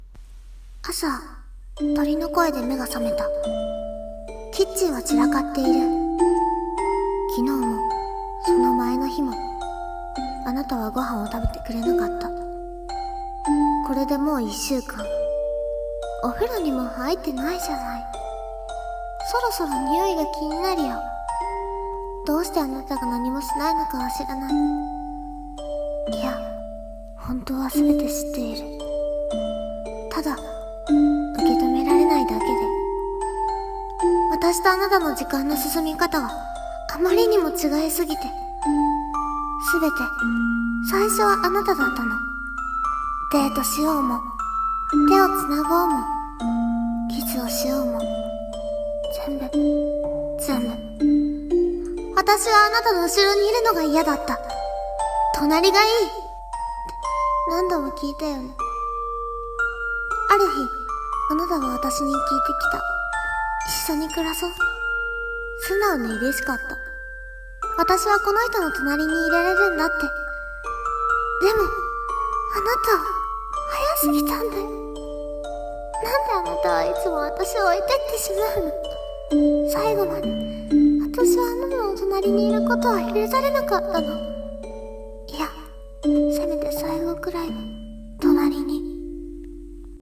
時間。【一人朗読】